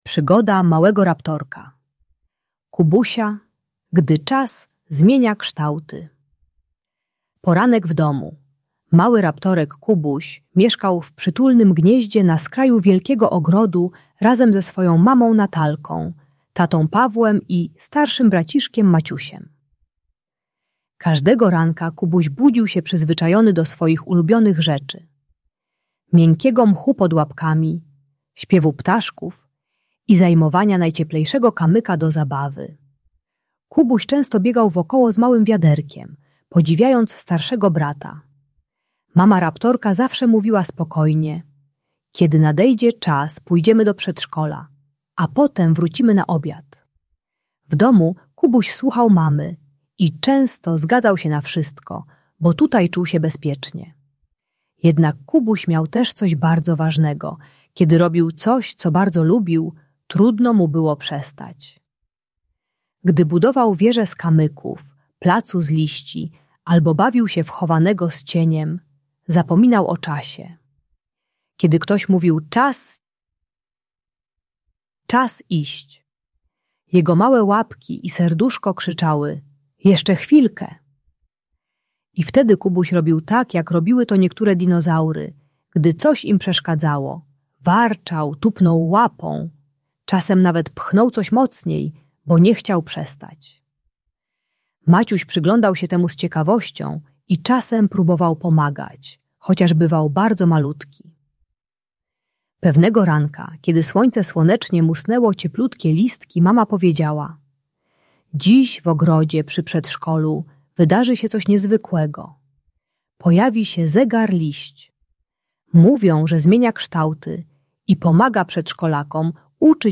Przygoda Małego Raptorka - Przedszkole | Audiobajka